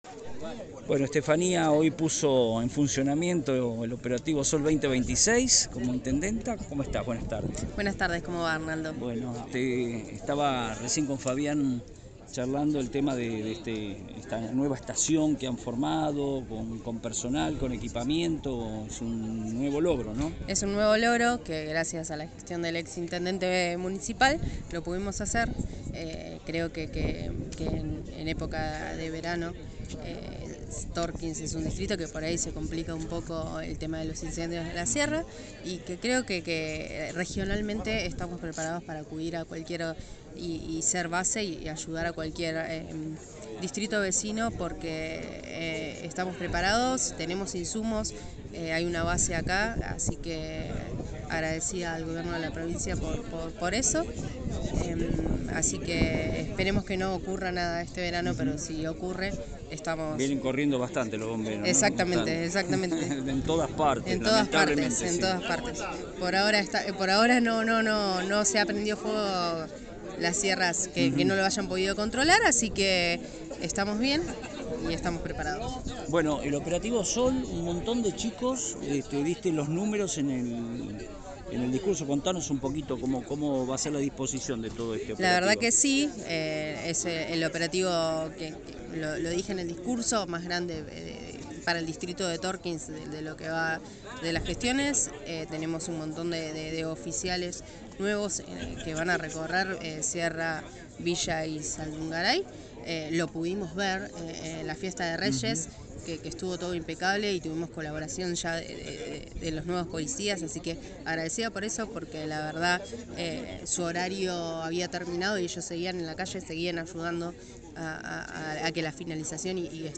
En el marco del inicio oficial del Operativo Sol 2026, la intendenta de Tornquist, Estefanía Bordoni, brindó detalles sobre la logística de seguridad para esta temporada y realizó un balance positivo tras el éxito de la reciente Fiesta de Reyes.